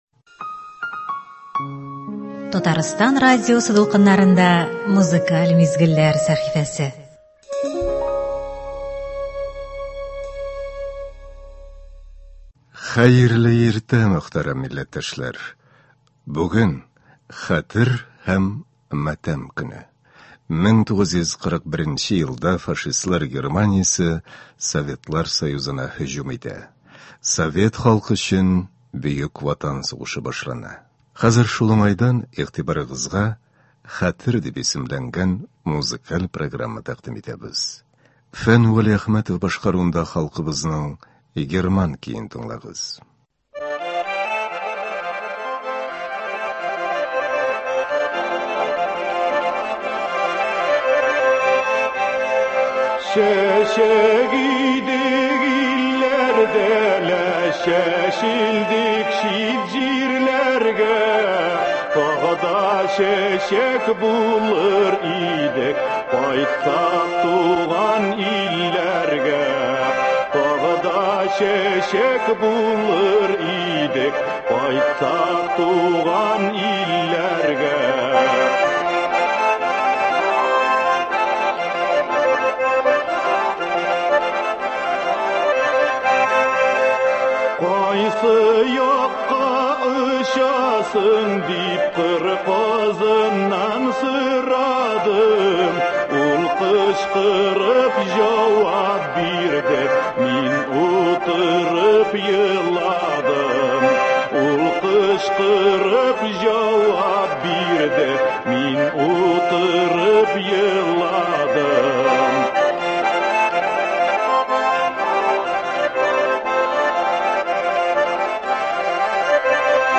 Бүгенге Хәтер көненә багышлап әзерләнгән концерт.